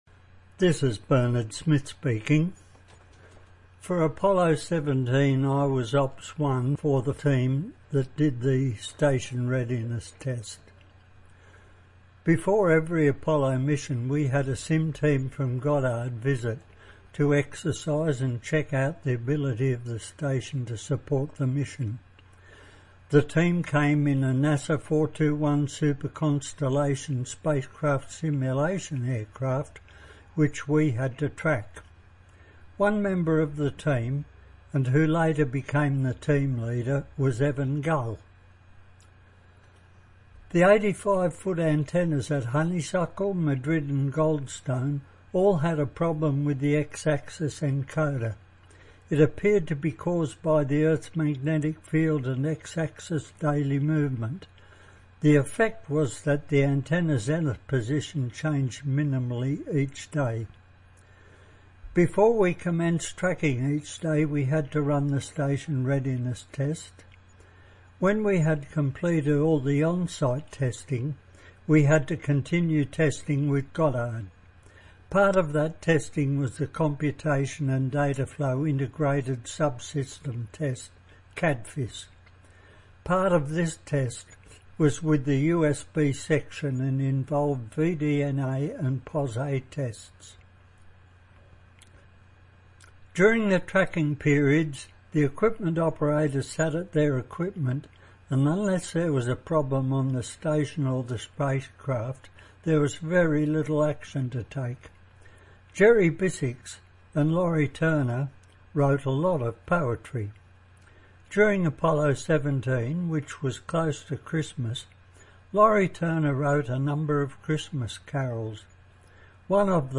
I recorded those words with a pipe organ accompaniment.